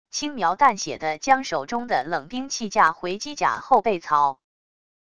轻描淡写地将手中的冷兵器架回机甲后背槽wav音频